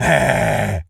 gorilla_angry_05.wav